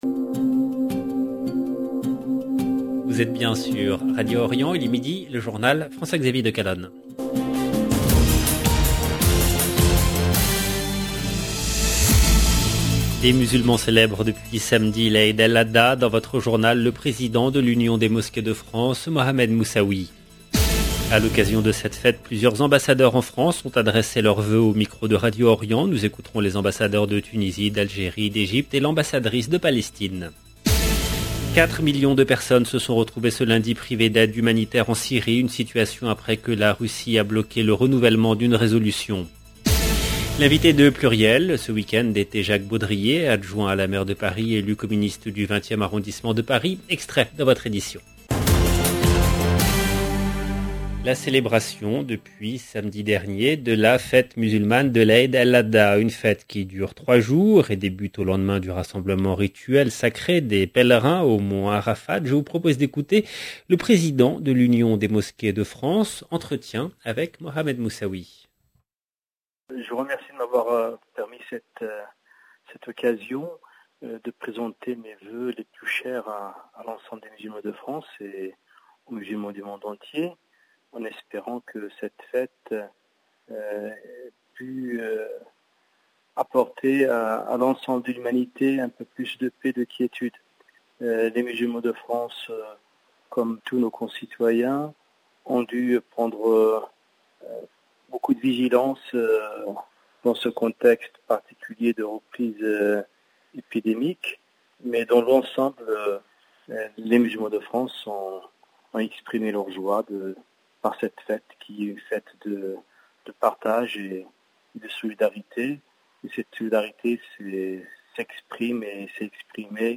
A l’occasion de cette fête, plusieurs ambassadeurs de France ont adressé leurs vœux au micro de Radio Orient. Nous écouterons les ambassadeurs de Tunisie, d’Algérie, d’Egypte et l’ambassadrice de Palestine. 4 millions de personnes se sont retrouvées ce lundi privées d'aide humanitaire.